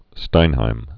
(stīnhīm, shtīn-)